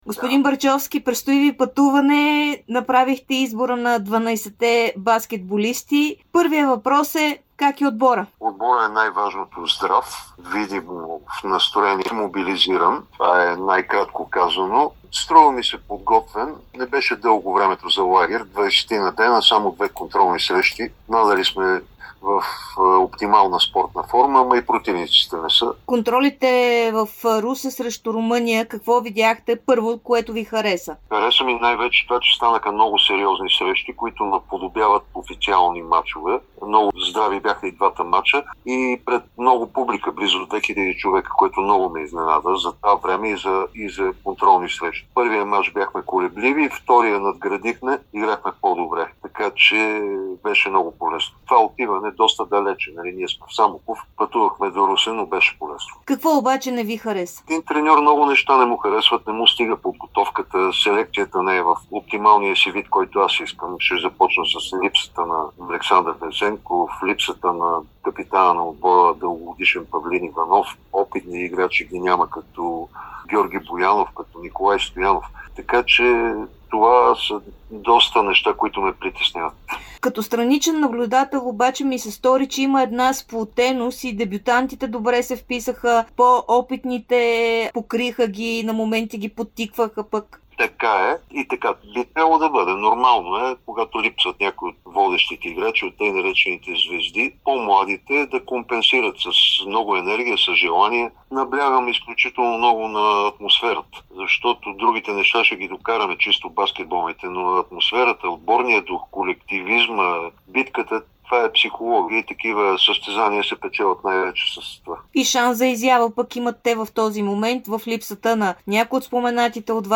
специално интервю за Дарик радио и dsport